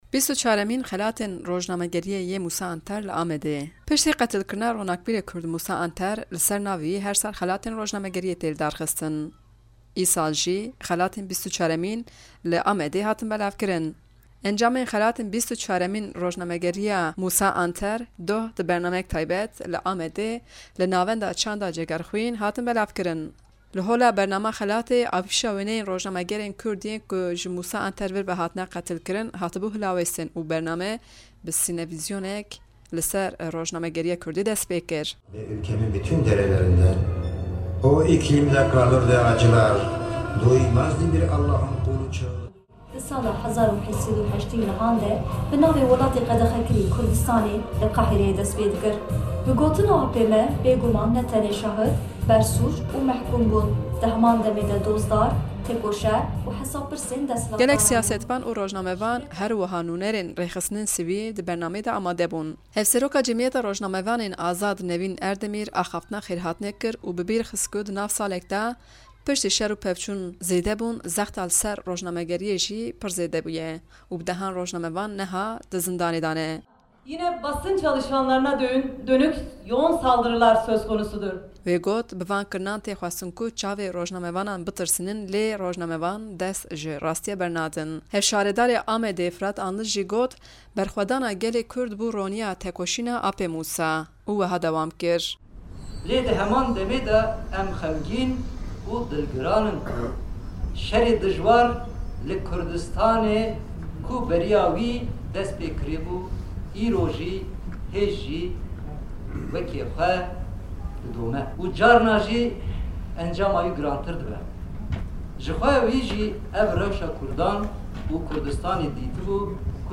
Encamên xelatên 24mîn, doh di bernameyek taybet de, li Amedê li Navenda Çanda Cegerxwînê hatin belavkirin.